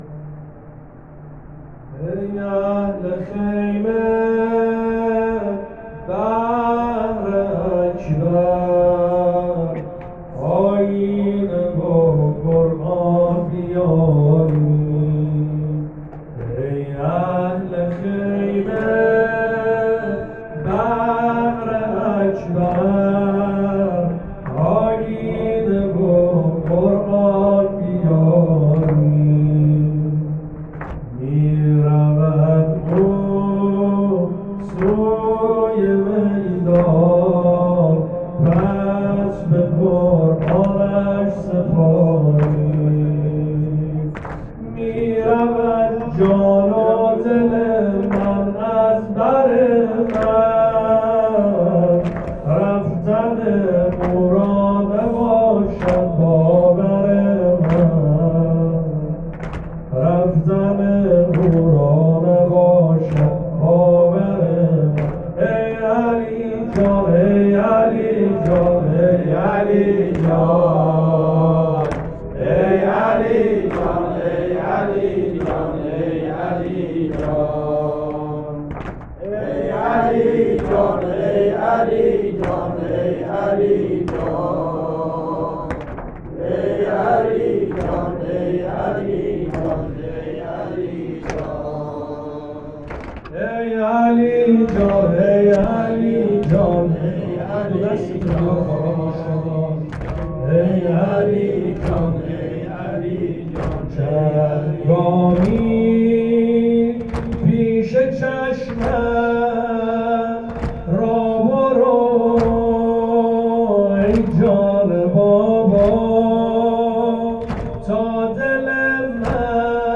مداحی
شب ششم محرم ۱۴۰۱